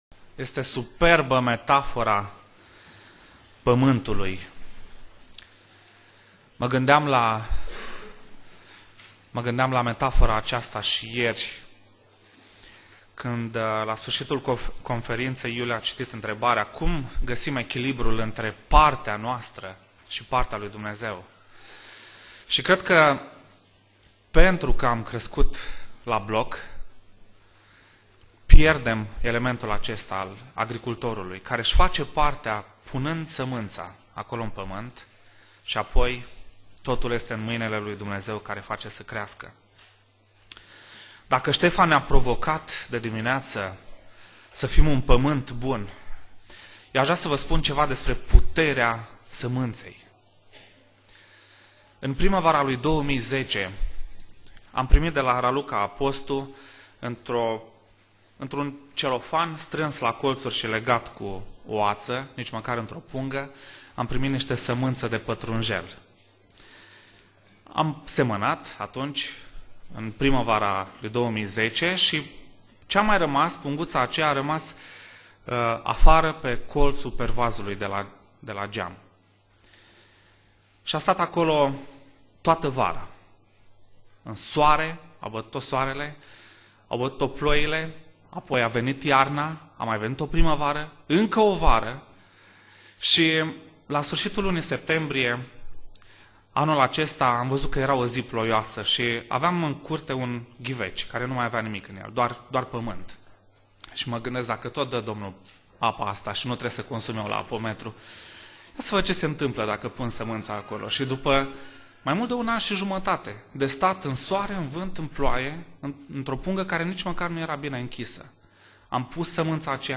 Predica Exegeza - Matei 5c